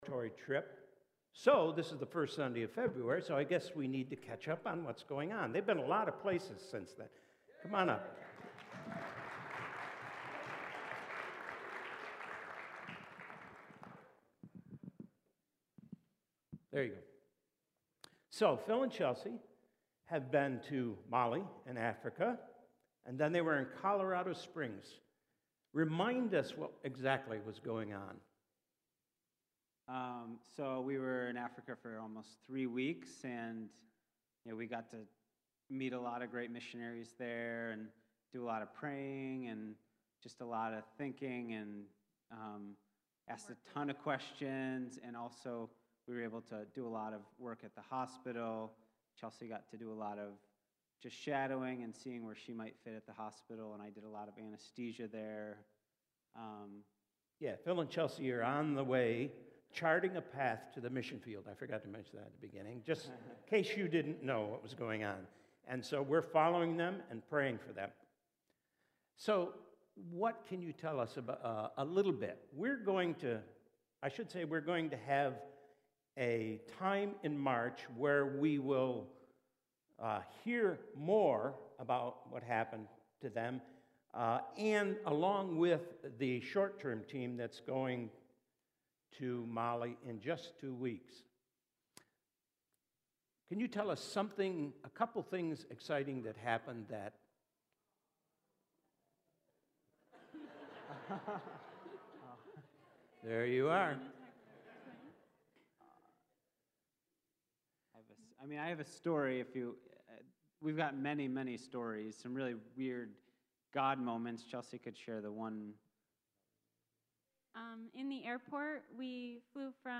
Sermon starts at 7:30. Luke 15 - Pointing to the Father's reckless love for us